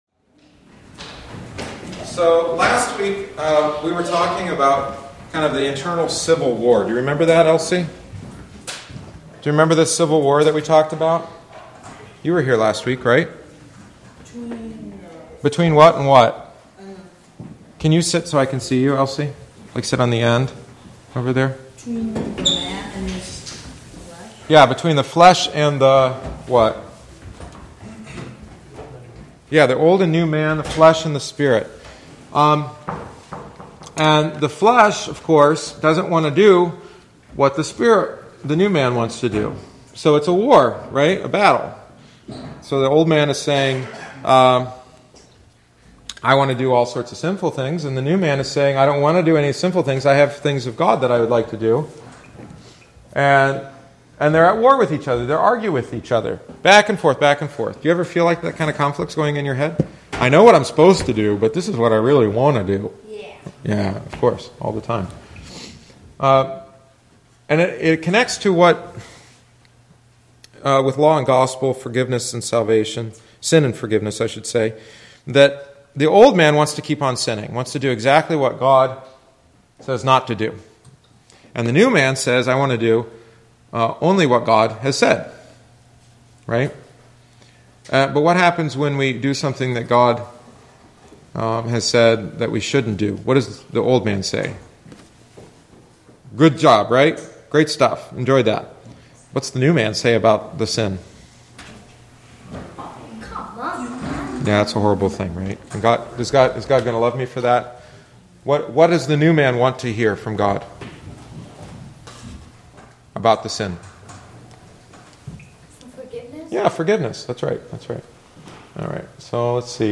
The following is the eighteenth week’s lesson.